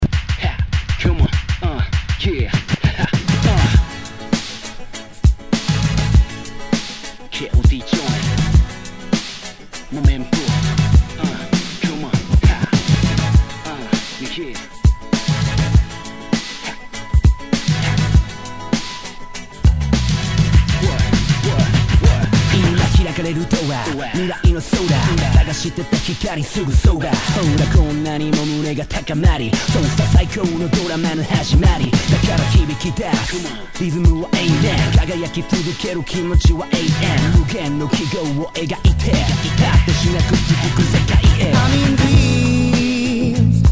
Tag       Japan R&B